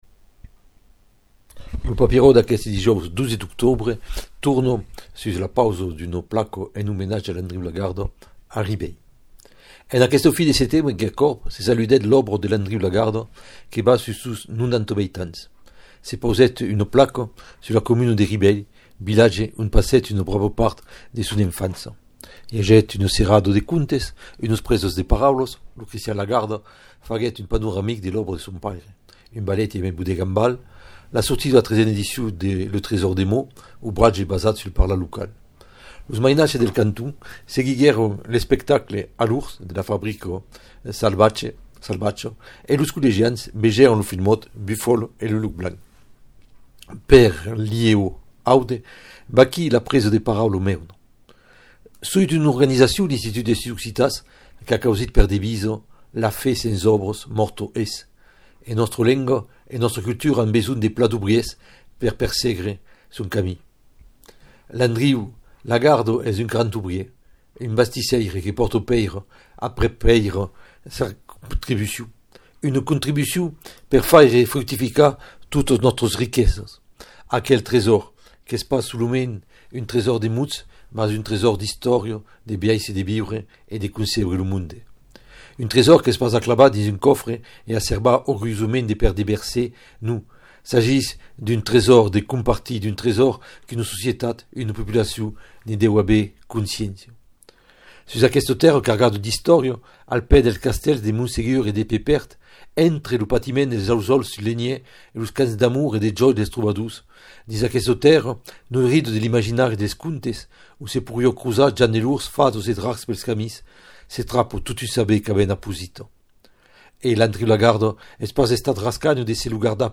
Per l’IEO-Aude, vaquí la presa de paraula meuna :